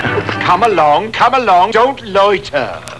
Dr. Smith telling the Robot: "Come along, don't loiter"